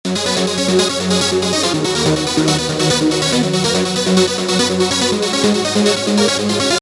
Как свести арпеджио и пэд?(Uplifting trance)
У обоих очень богатые тембры, оба играют вместе в нескольких регистрах где-то от 150 до 10 000 гц.
Вот лид-пэд вообще без какой-либо обработки (ну кроме фазера на пэде )
Мне кажется, что в моем примере не лид не пробивается, а как раз пэд теряется по ним Вложения My lead-pad.mp3 My lead-pad.mp3 270,3 KB · Просмотры: 436